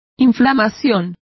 Complete with pronunciation of the translation of inflammations.